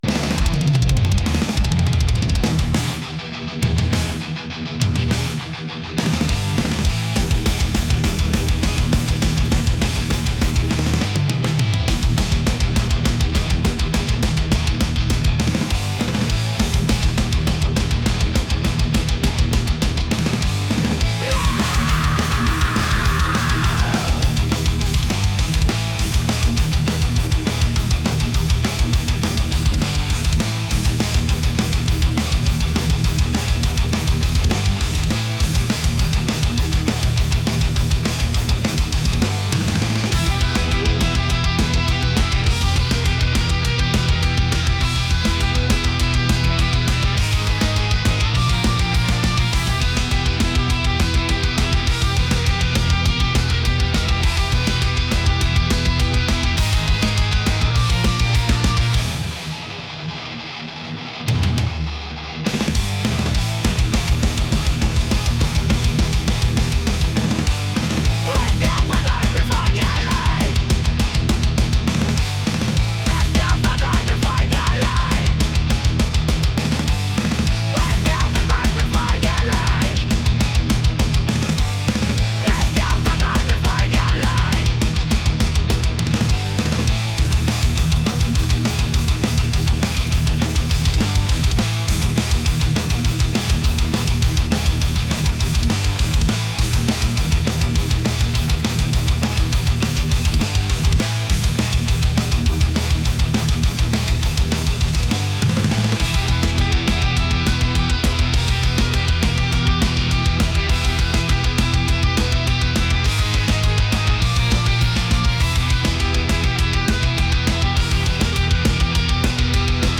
metal | heavy | aggressive